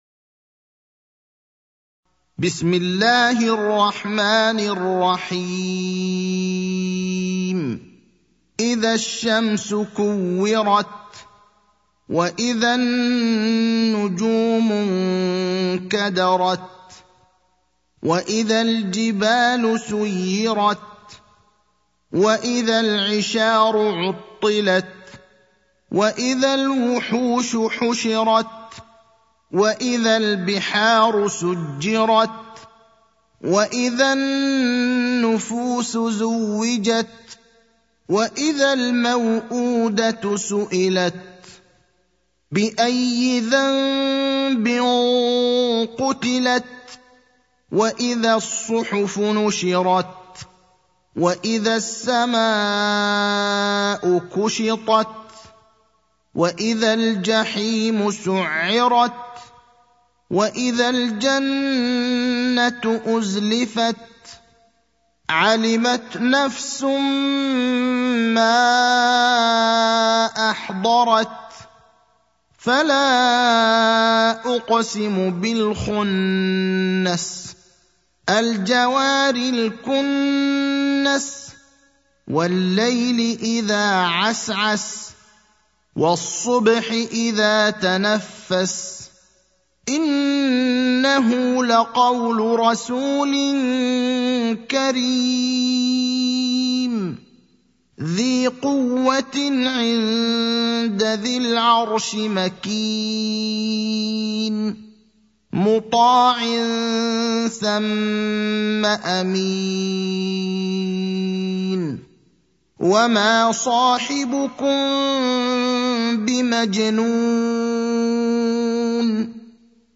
المكان: المسجد النبوي الشيخ: فضيلة الشيخ إبراهيم الأخضر فضيلة الشيخ إبراهيم الأخضر التكوير (81)